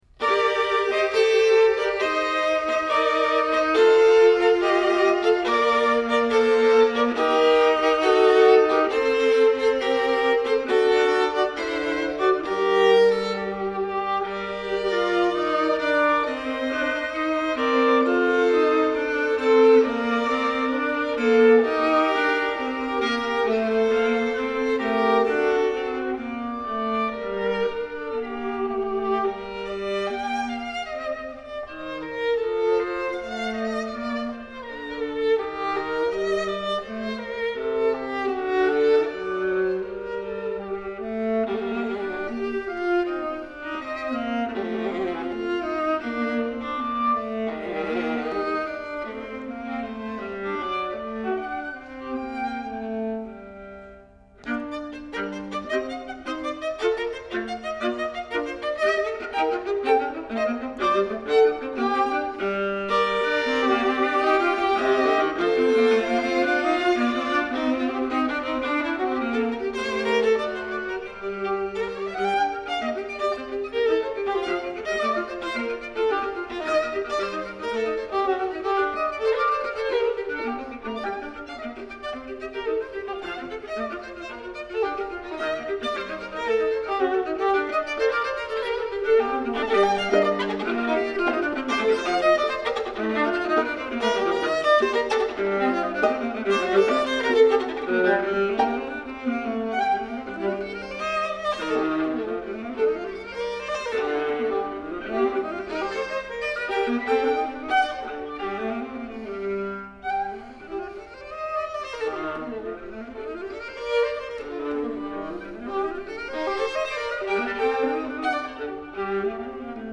Passacaglia-for-violin-and-viola.mp3